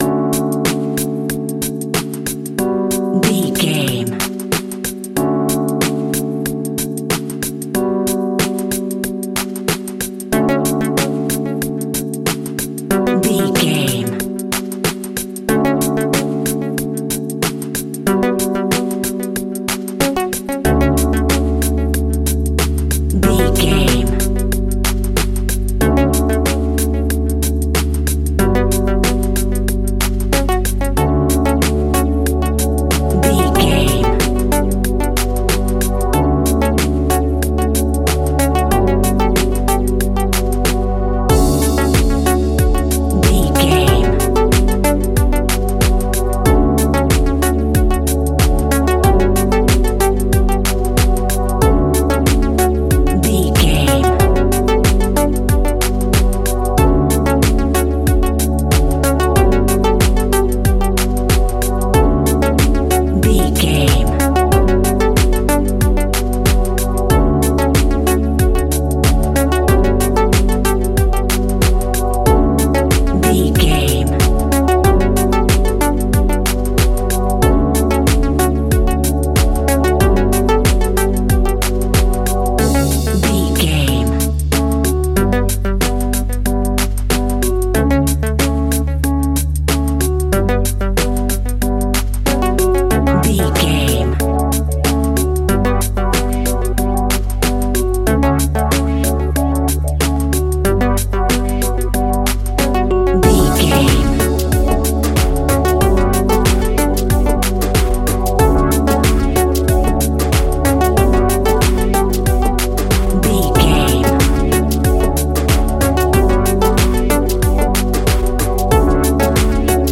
Ionian/Major
D
hip hop instrumentals
downtempo
synth lead
synth bass
synth drums